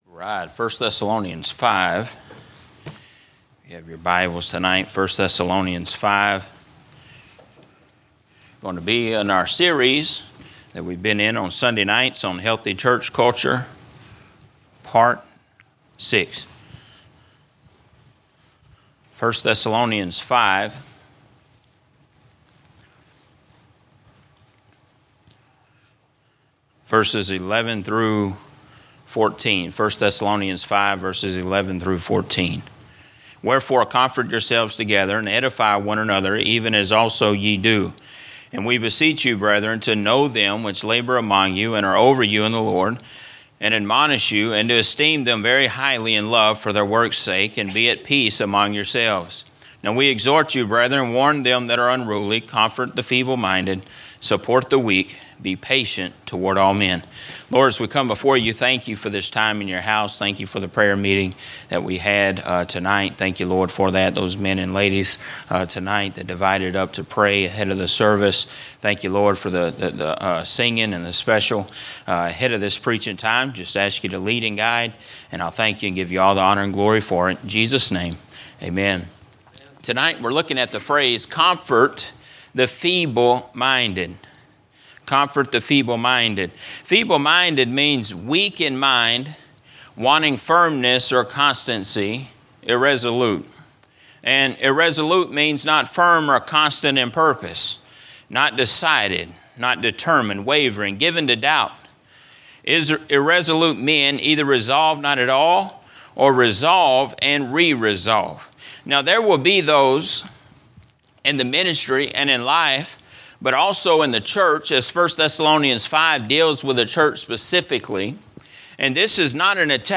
A message from the series "General Preaching." A look at the story of Jesus healing the ten lepers